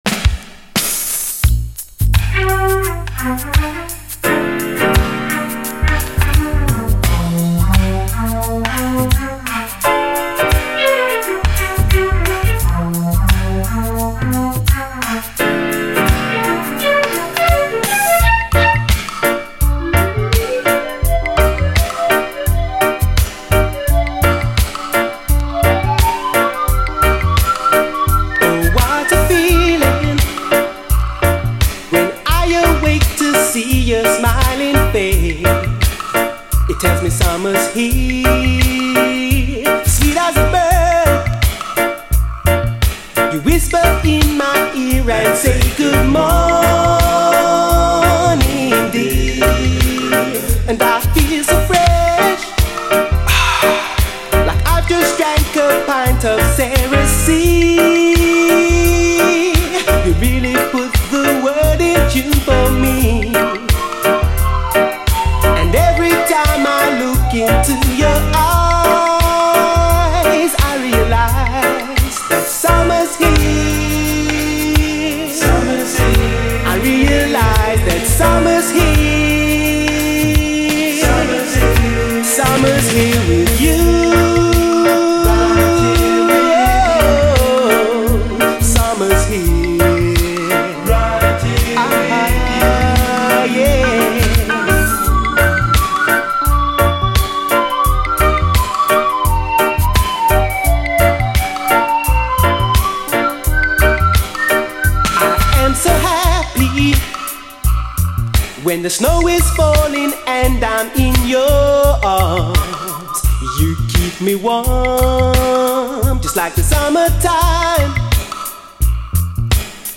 REGGAE
綺麗なシンセ使いと爽快なメロディー、タイトル通りに夏仕様な最高UKラヴァーズ！